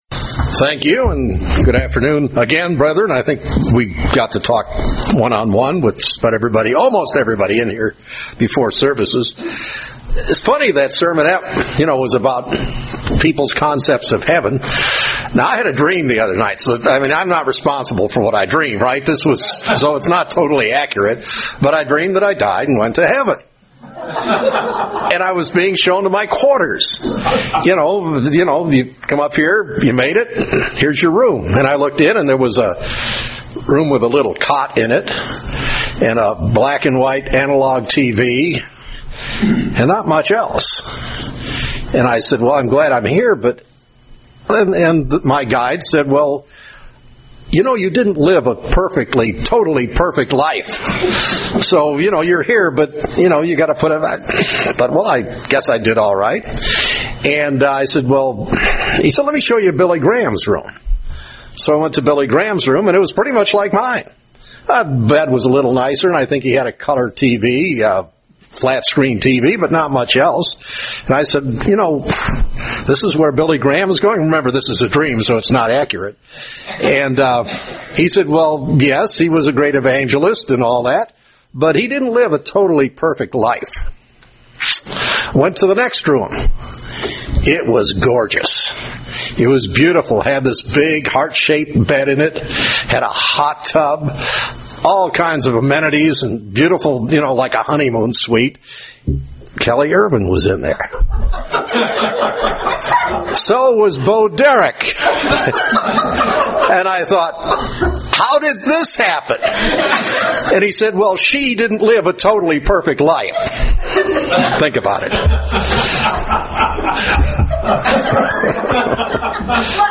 Given in Northwest Arkansas
UCG Sermon Studying the bible?